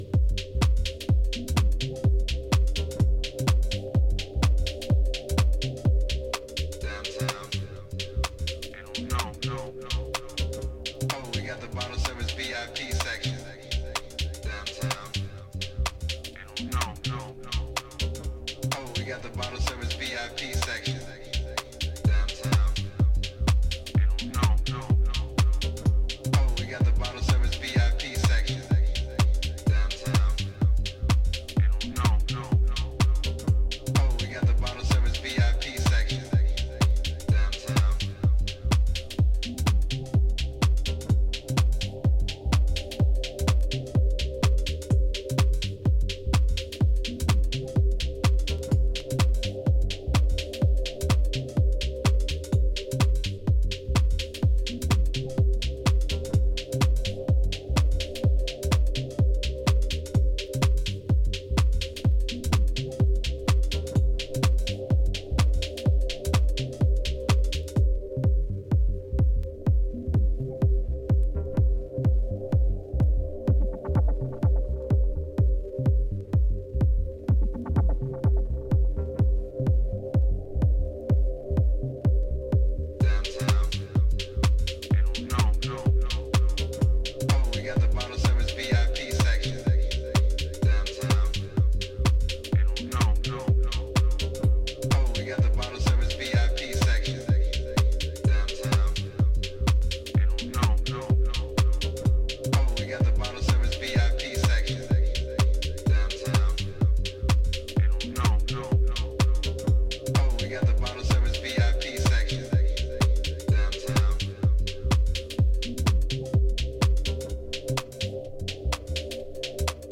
Deep house
Detroit house